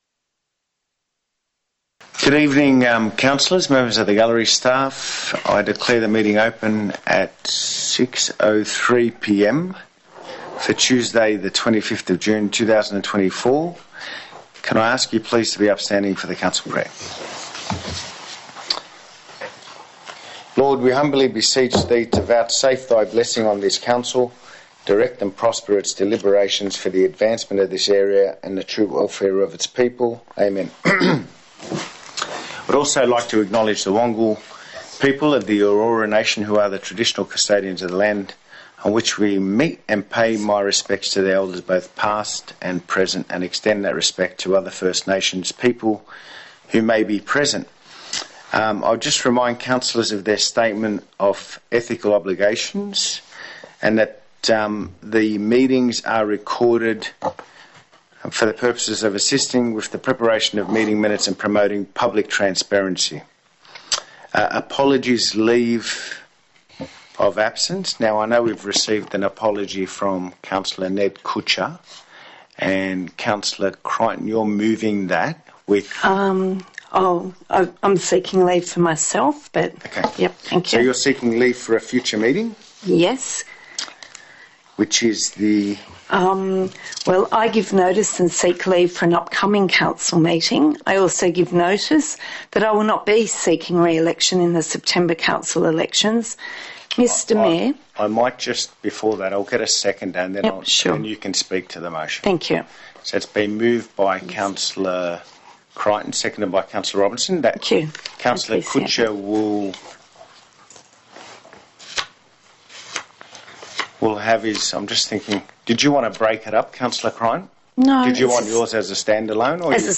Council Meeting - 25 June 2024
Notice is hereby given that a meeting of the Council of Burwood will be held in the Conference Room, 2 Conder Street, Burwood on Tuesday 25 June 2024 at 6.00 pm to consider the matters contained in the attached Agenda.